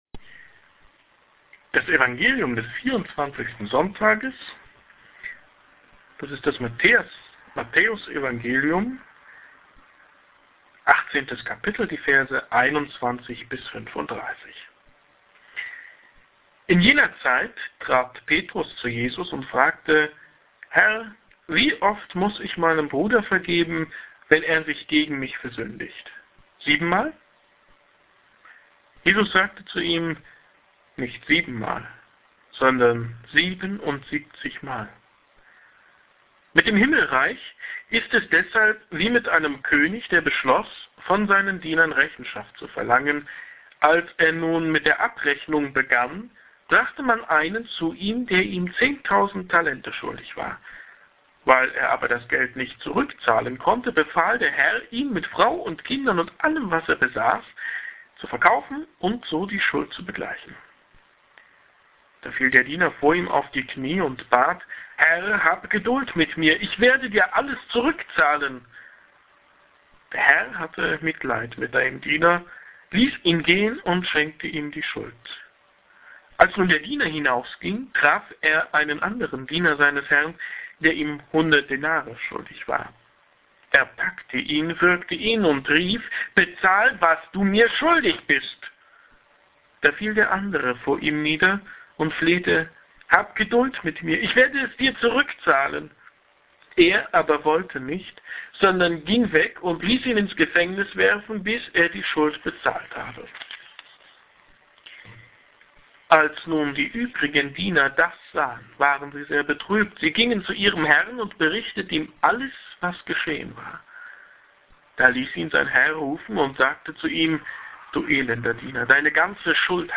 Immer und immer und immer und immer und immer … wieder sollst du vergeben. Predigt zum 24. So. i. Jkr. Lj. A
hier-klickt-die-predigt.mp3